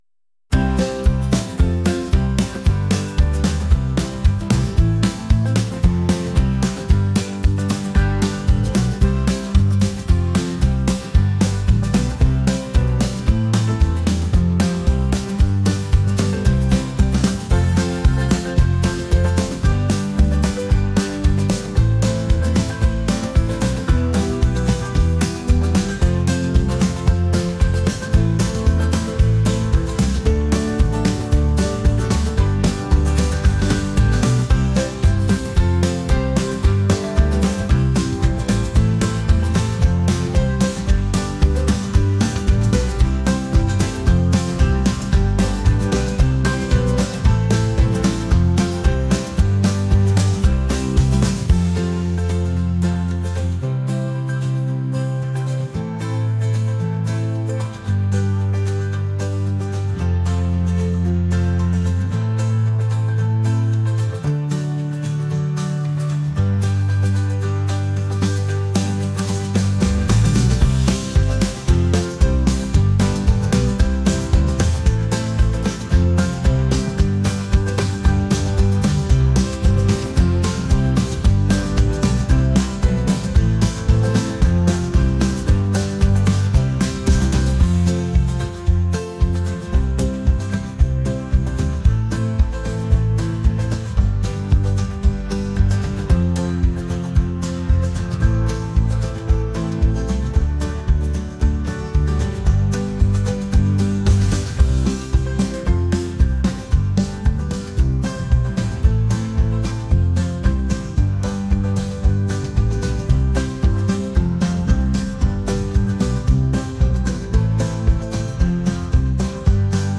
energetic | folk